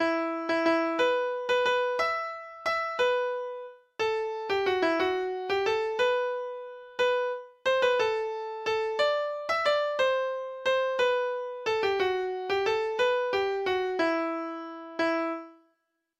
Lytt til data-generert lydfil